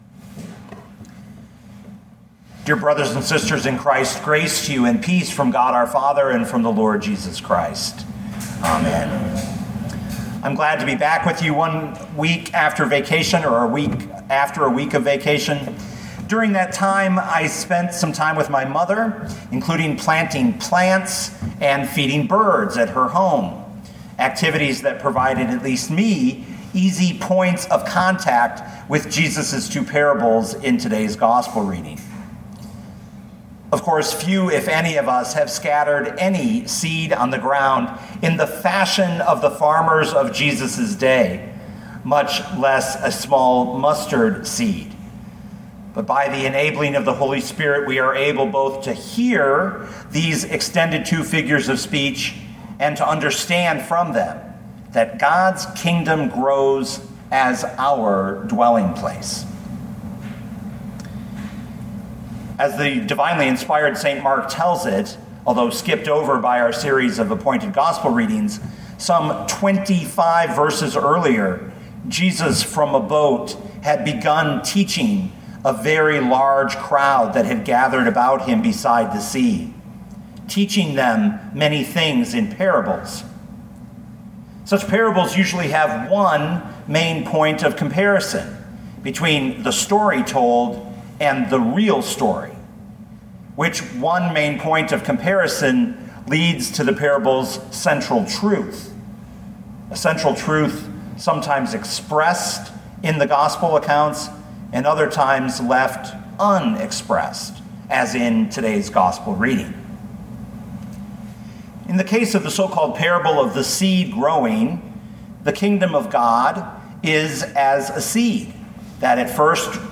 2021 Mark 4:26-34 Listen to the sermon with the player below, or, download the audio.